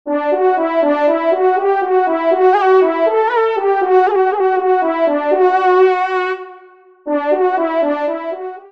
FANFARE
Extrait de l’audio « Ton de Vènerie »
Pupitre de Chant